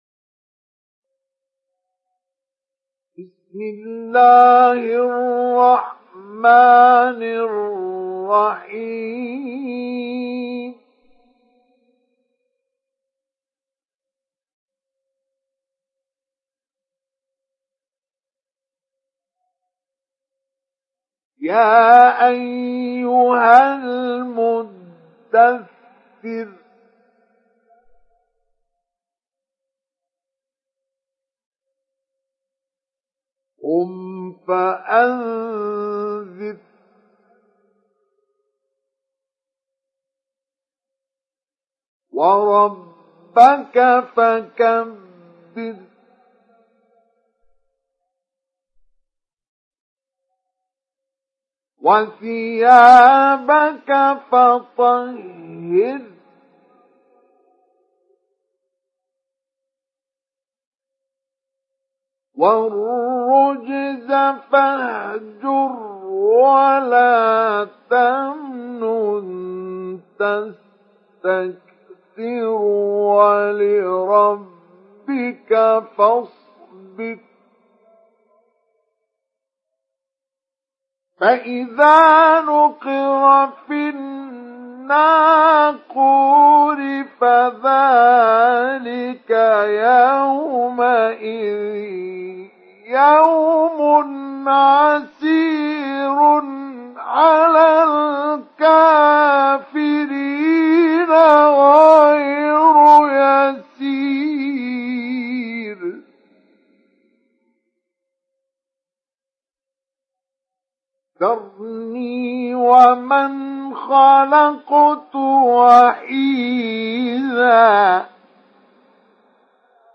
Sourate Al Muddathir Télécharger mp3 Mustafa Ismail Mujawwad Riwayat Hafs an Assim, Téléchargez le Coran et écoutez les liens directs complets mp3
Télécharger Sourate Al Muddathir Mustafa Ismail Mujawwad